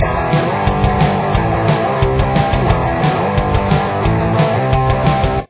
This riff is pretty standard and is in drop D tuning.
Chorus